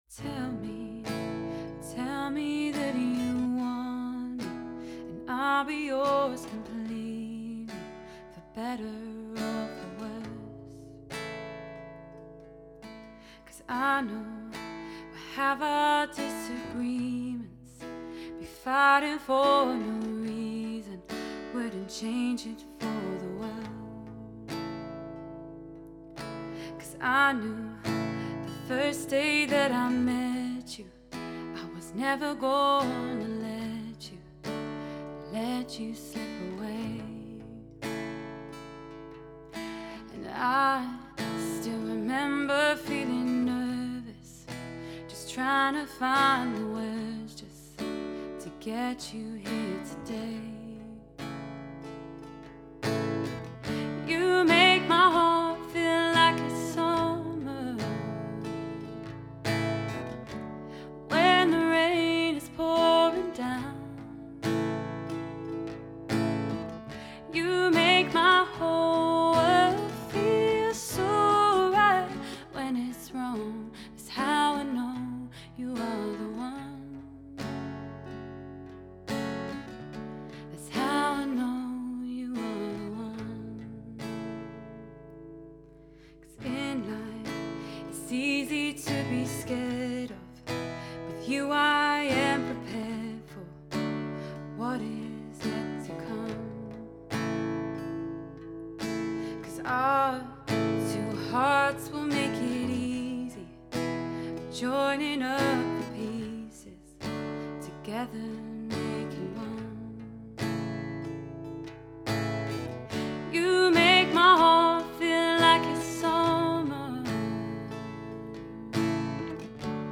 and piano
From £357 + travel | Singer Guitarist